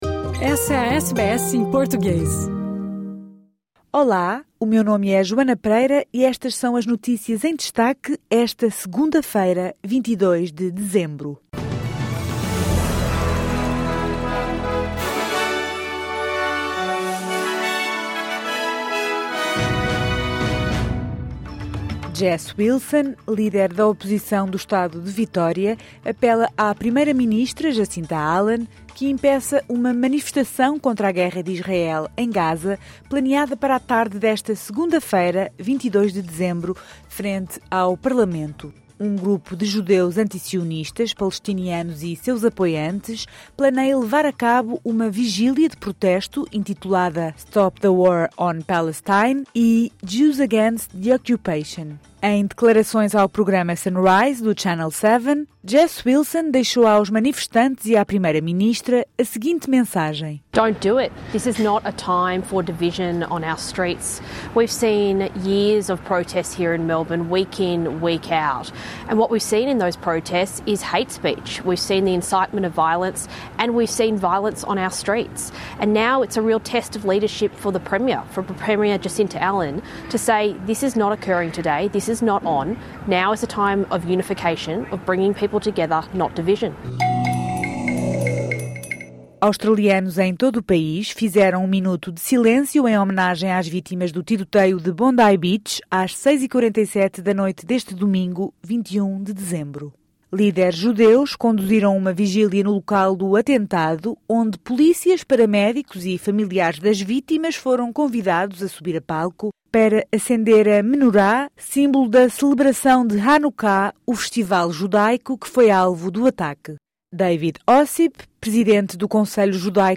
Australianos em todo o país fizeram um minuto de silêncio em homenagem às vítimas do tiroteio de Bondi Beach às 6h47 da noite deste domingo. Estas e outras notícias em destaque no noticiário de hoje.